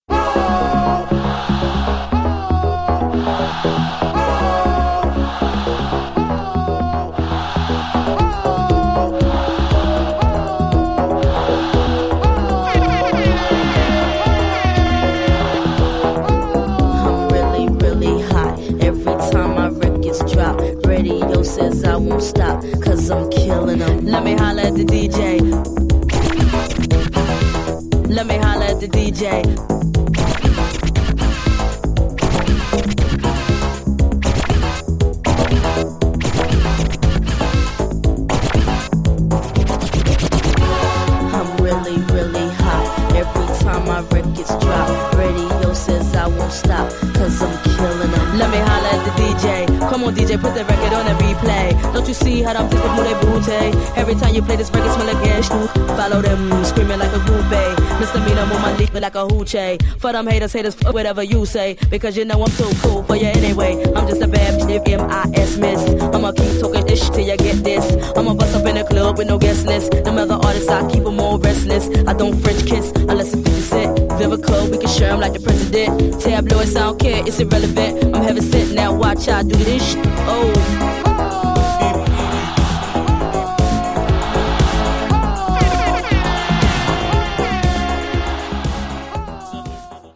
118 bpm
Clean Version